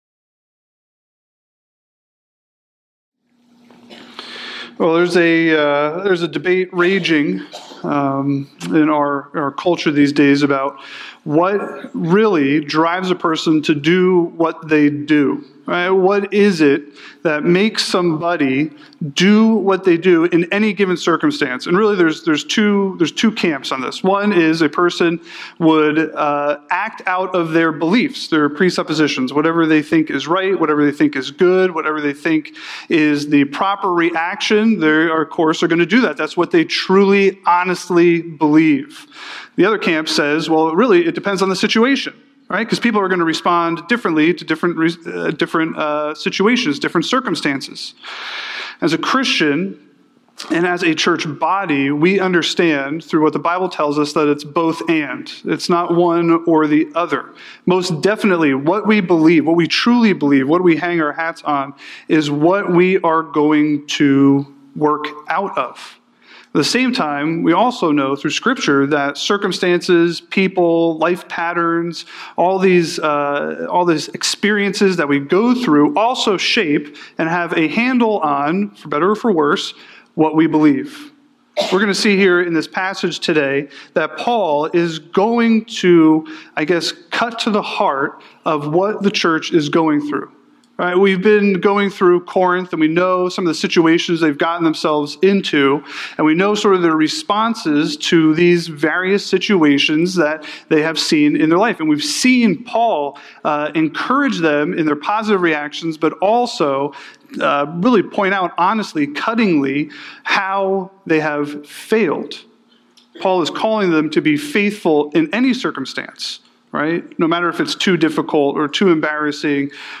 An expositional preaching series through Paul's first letter to the church at Corinth.